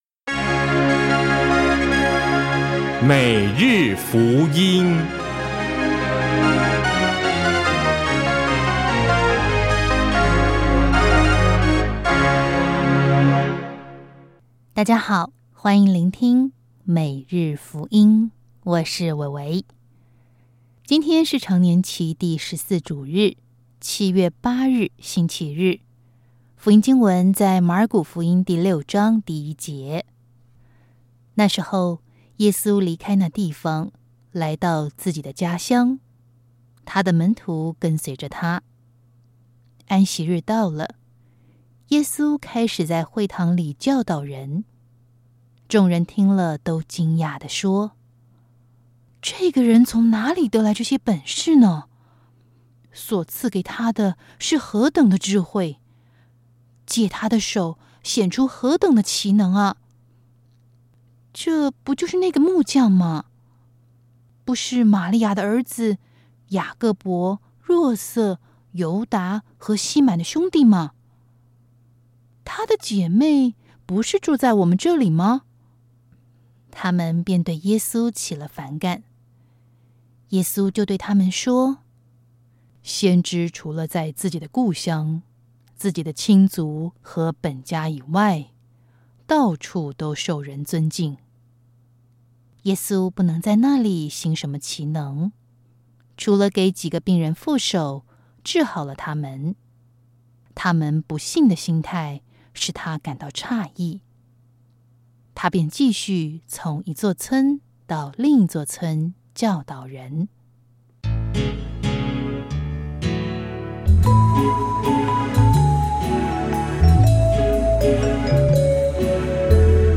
首页 / 证道